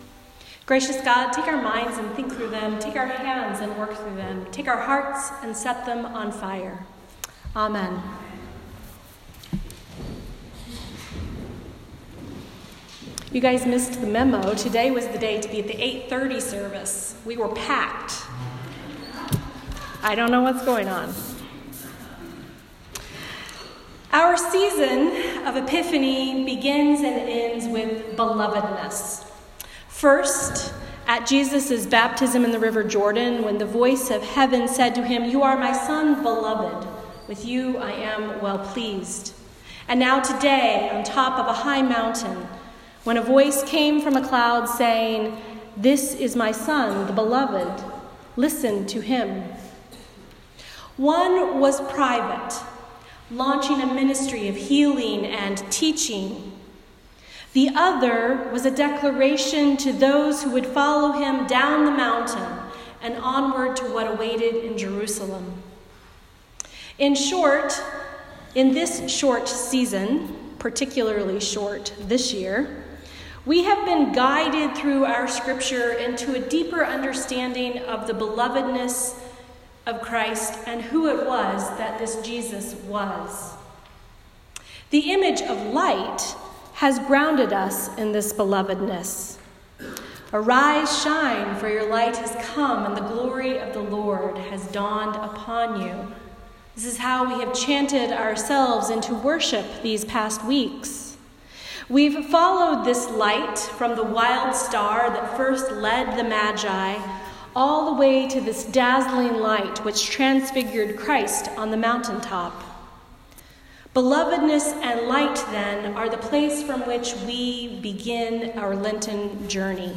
A Sermon for the Last Sunday after the Epiphany